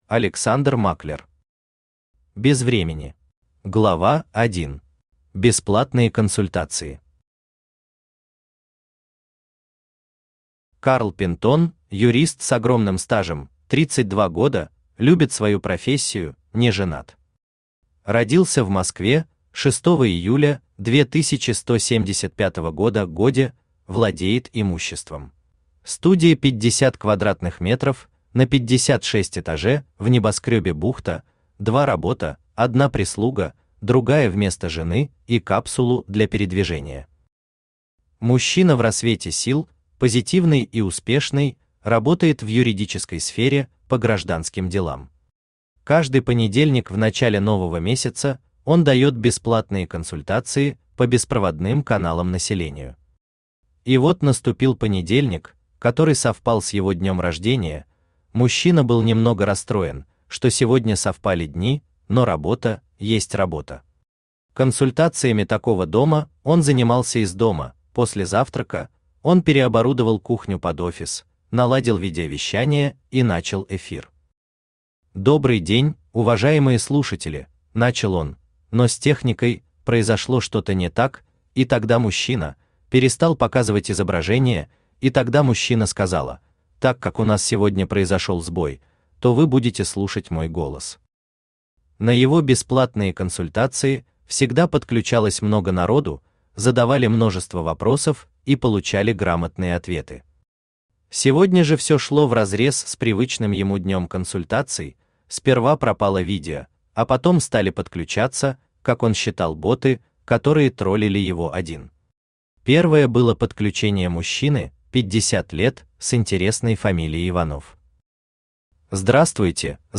Aудиокнига Без времени Автор Александр Германович Маклер Читает аудиокнигу Авточтец ЛитРес.